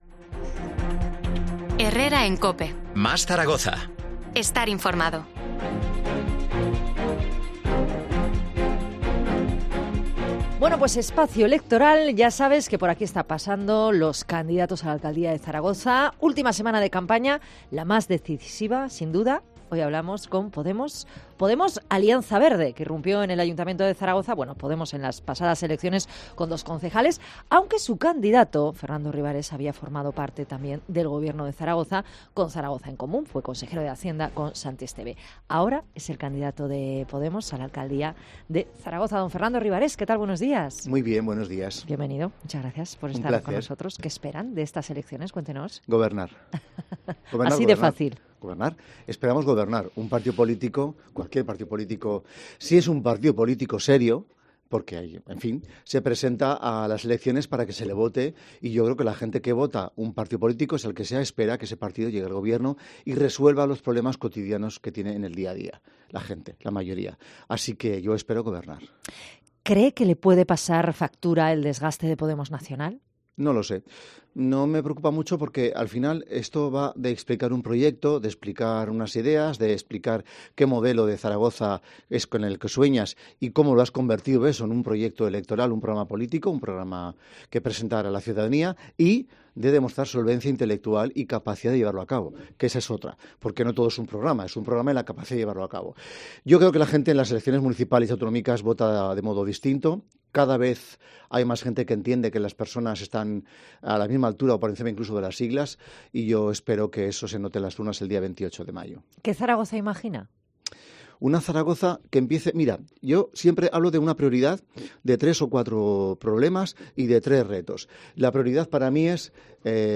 El candidato de Podemos-Alianza Verde ha pasado este lunes por los estudios de COPE Zaragoza para desgranar alguna de las propuestas de su formación...
Entrevista Fernando Rivarés, candidato de Podemos-Alianza Verde a la alcaldía de Zaragoza